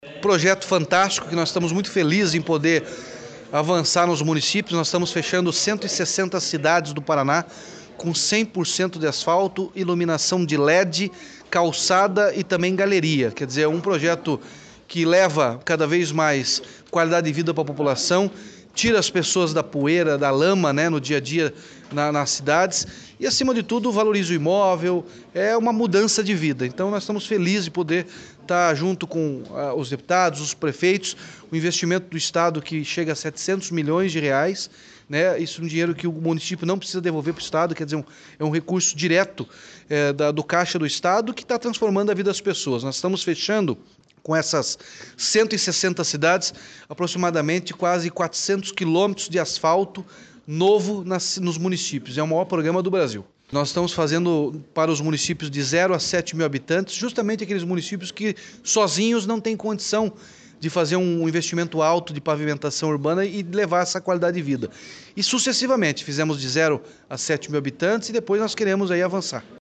Sonora do governador Ratinho Junior sobre o repasse de recursos para mais 7 municípios pelo Asfalto Novo, Vida Nova